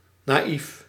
Ääntäminen
IPA : /ˈɡɔːmləs/